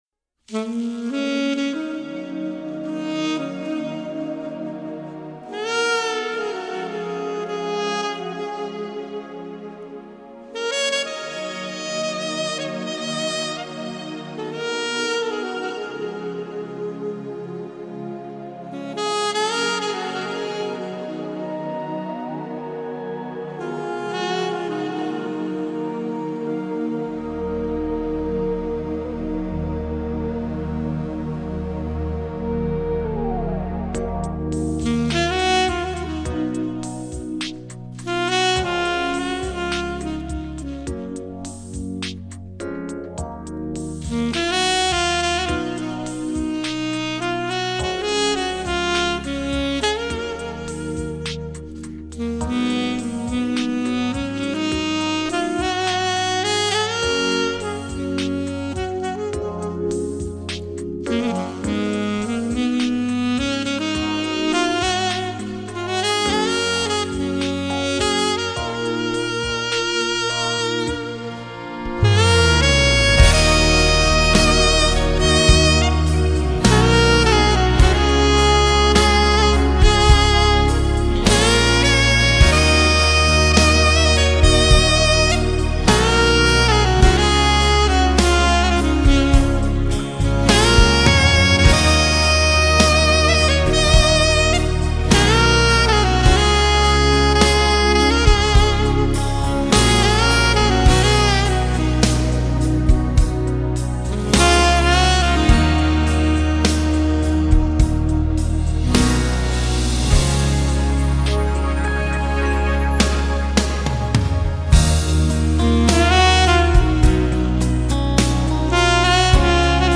Xroticheskij_Saksofon_For_You_.mp3